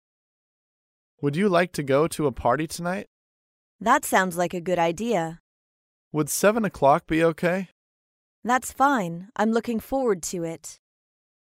在线英语听力室高频英语口语对话 第75期:外出参加派对的听力文件下载,《高频英语口语对话》栏目包含了日常生活中经常使用的英语情景对话，是学习英语口语，能够帮助英语爱好者在听英语对话的过程中，积累英语口语习语知识，提高英语听说水平，并通过栏目中的中英文字幕和音频MP3文件，提高英语语感。